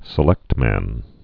(sĭ-lĕktmăn, -mən)